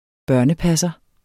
Udtale [ ˈbɶɐ̯nə- ]